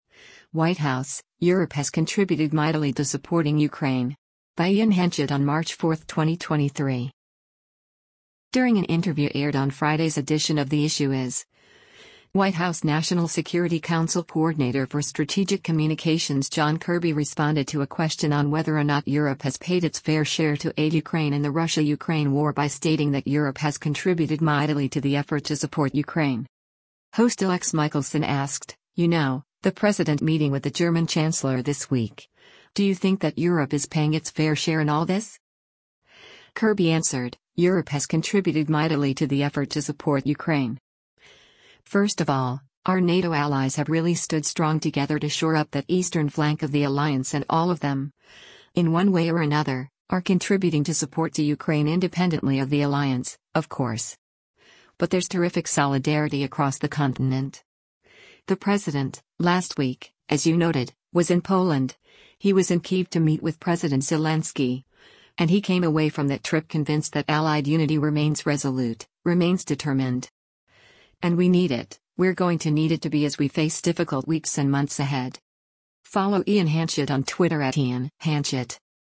During an interview aired on Friday’s edition of “The Issue Is,” White House National Security Council Coordinator for Strategic Communications John Kirby responded to a question on whether or not Europe has paid its fair share to aid Ukraine in the Russia-Ukraine war by stating that “Europe has contributed mightily to the effort to support Ukraine.”